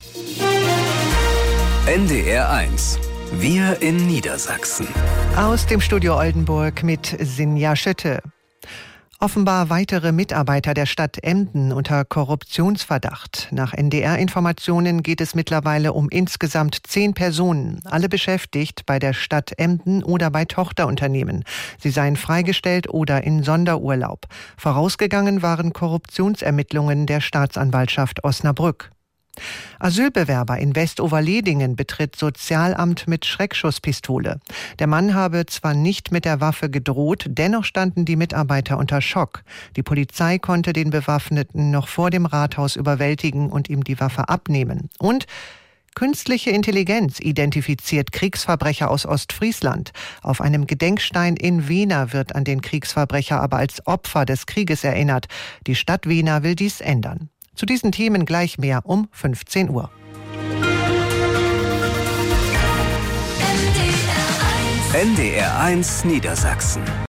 Nachrichten aus dem Studio Oldenburg